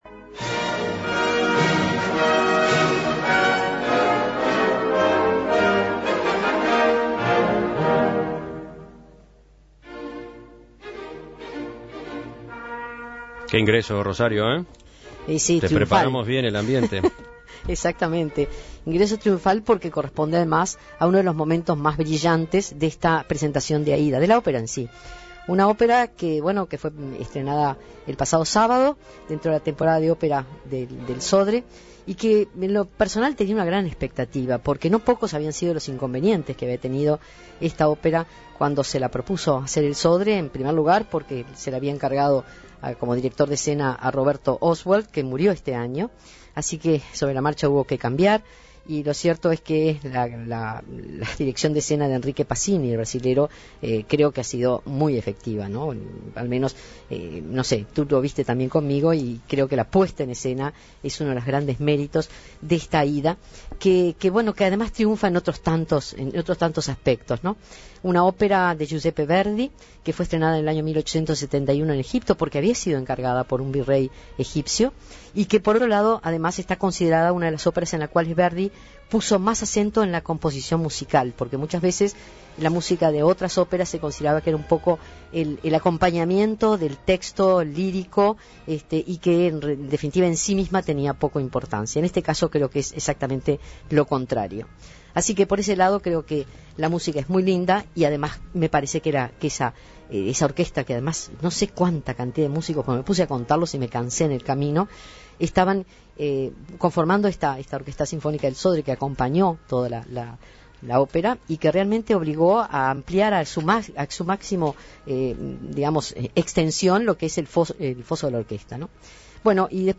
Ópera Aida, de Giuseppe Verdi en el Auditorio Adela Reta
Aída es una ópera en cuatro actos compuesta por Giussepe Verdi, basada en el drama escrito por Auguste Mariette, sobre el libreto de Camile du Locle y los versos de Antonio Ghislanzoni.